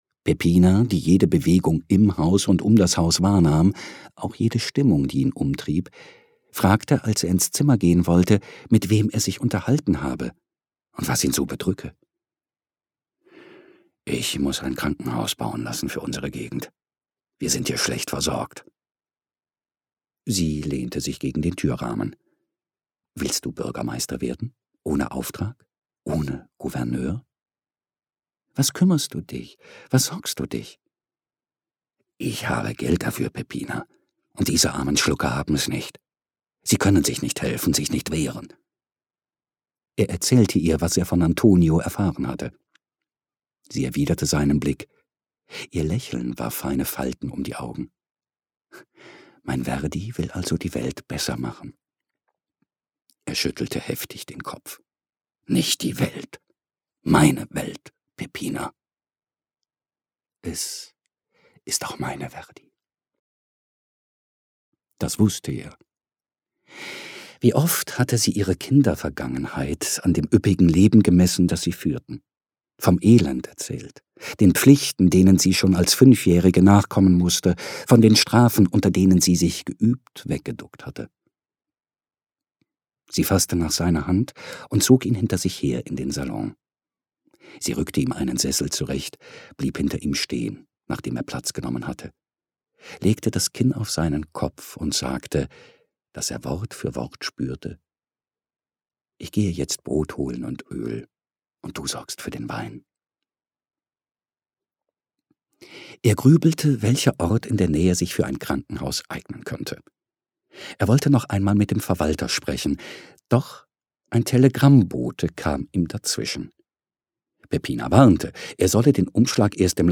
Verdi - Peter Härtling - Hörbuch